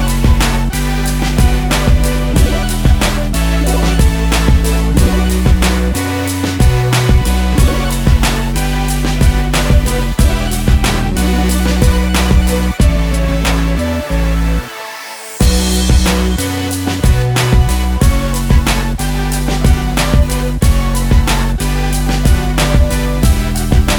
no Backing Vocals R'n'B / Hip Hop 3:39 Buy £1.50